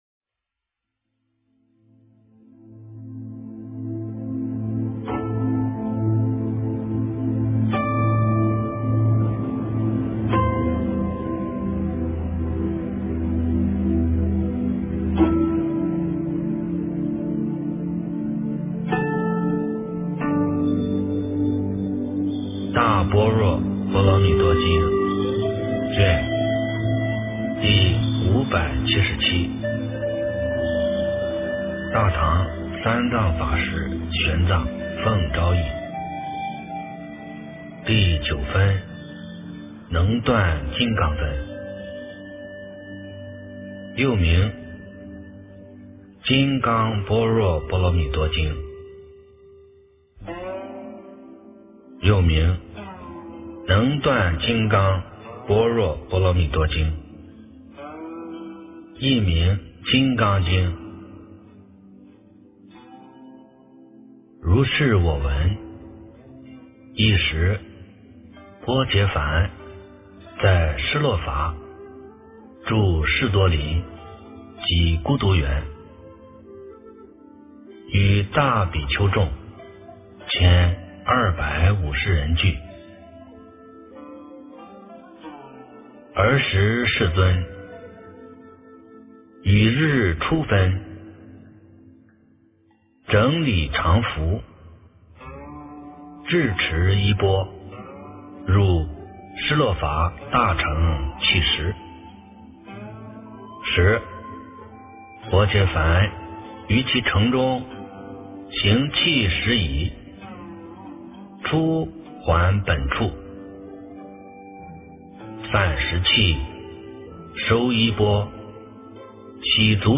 诵经
佛音 诵经 佛教音乐 返回列表 上一篇： 解深密经-7（念诵） 下一篇： 《华严经》80卷 相关文章 《妙法莲华经》授学无学人记品第九 《妙法莲华经》授学无学人记品第九--佚名...